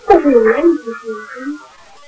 recorded at the battlefield.
It is a good quality recording of a Spirit lady with an Irish accent saying.....